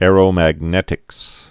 (ârō-măg-nĕtĭks)